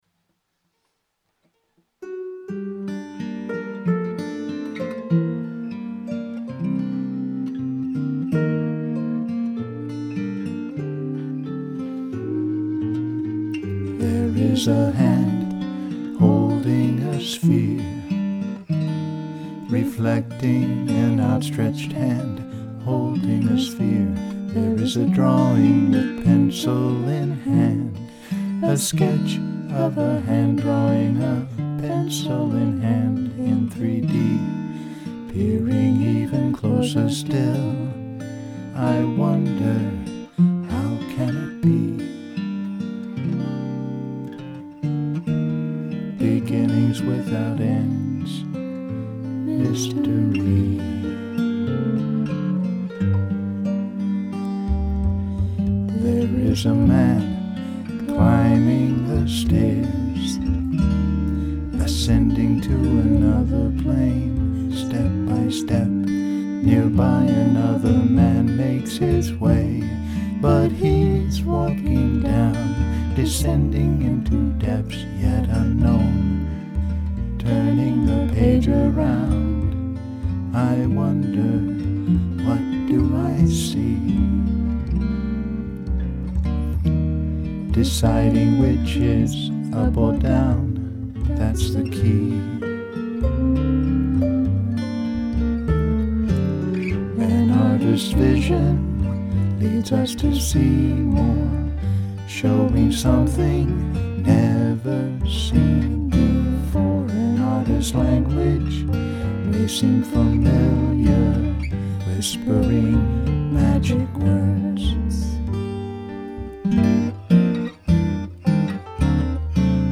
Vocals and Production
Guitar